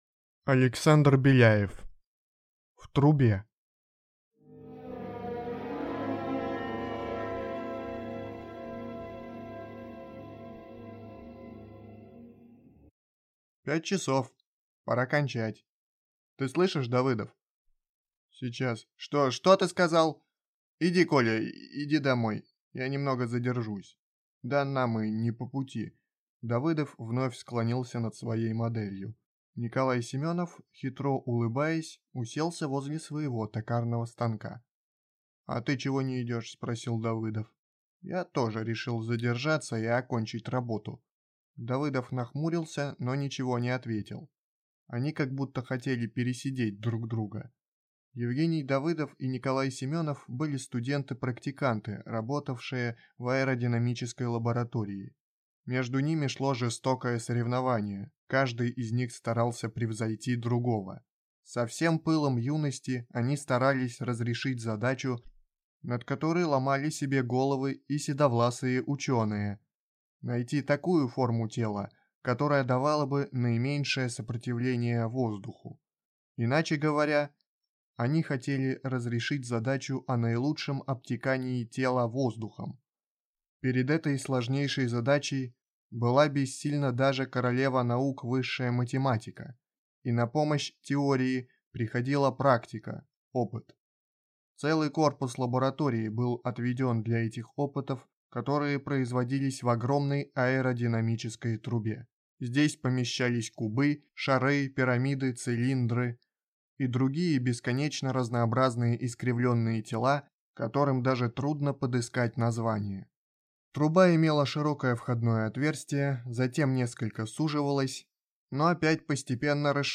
Аудиокнига В трубе | Библиотека аудиокниг